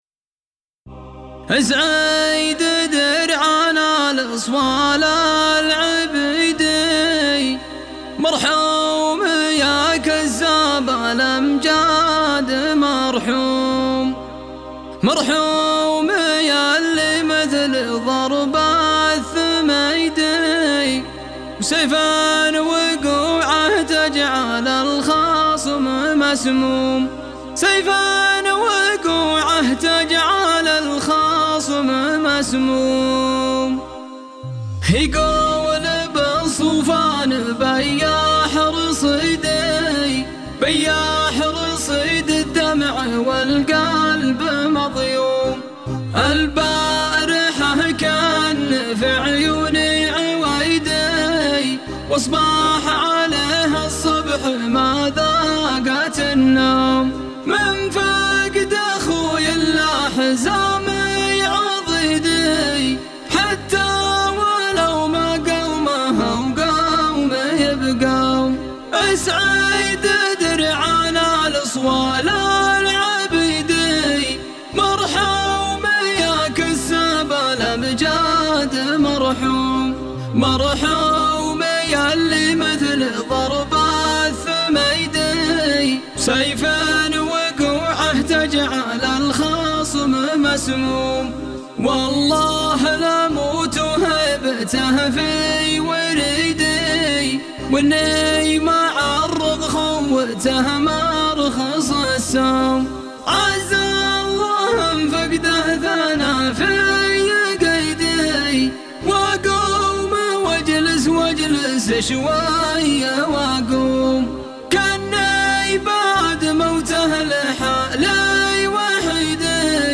مرثيه